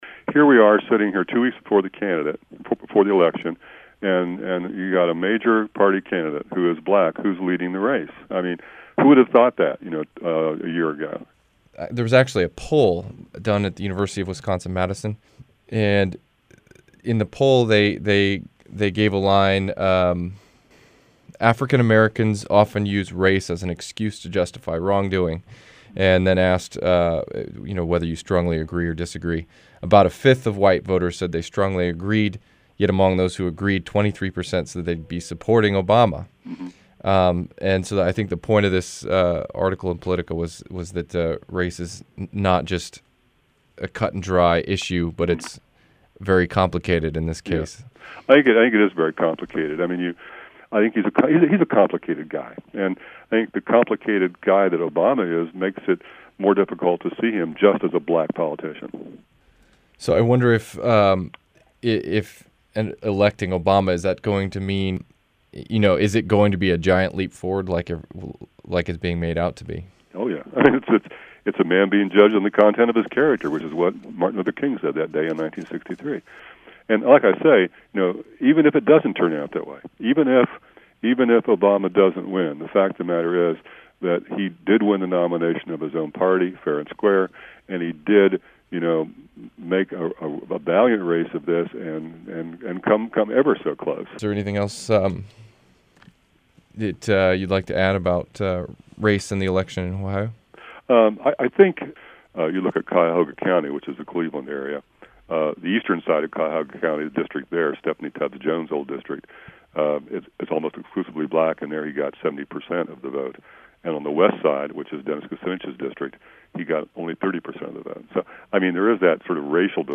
University of Wisconsin-Madison Big Ten poll mentioned in interview